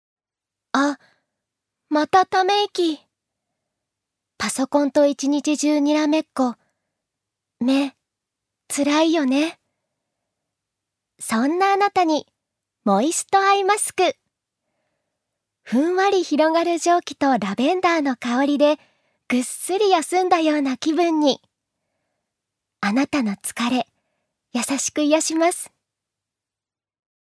預かり：女性
ナレーション３